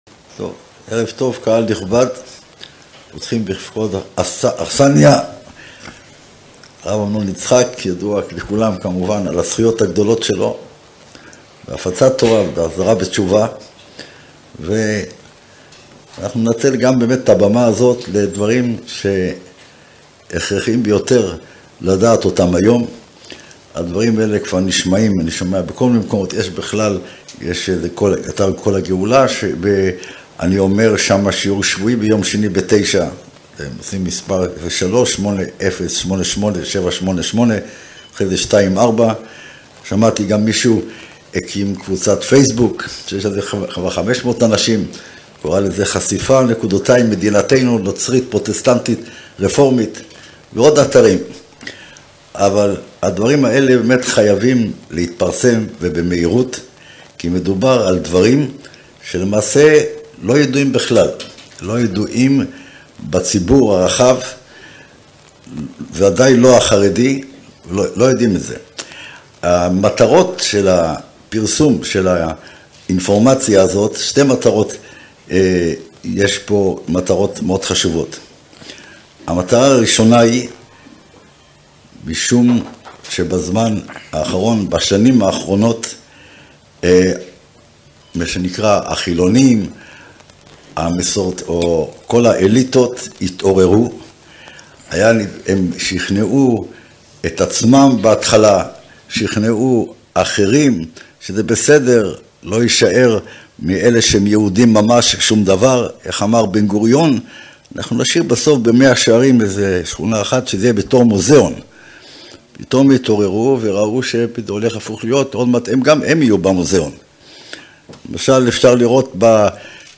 חשיפה המדע והחברה המערבית במדינת ישראל בנוים על הנצרות הפרוטסטנטית - הרצאה 1